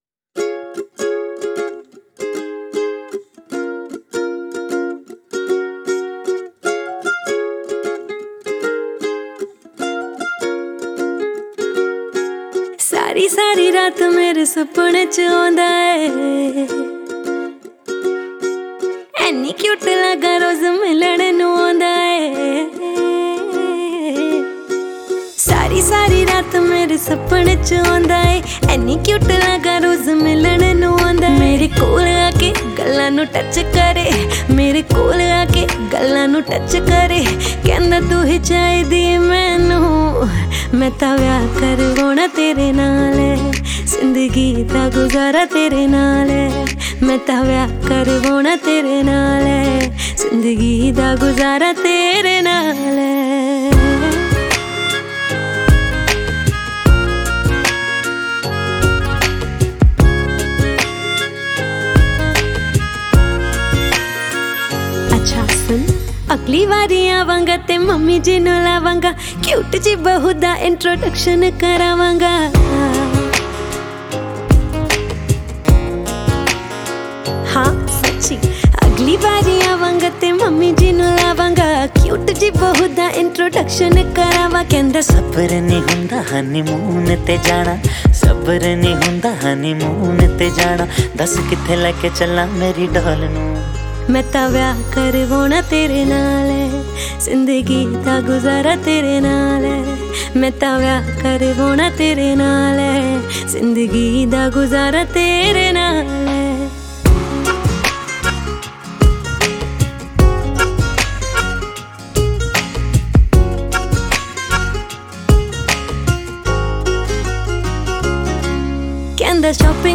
2020 Pop Mp3 Songs
Punjabi Bhangra MP3 Songs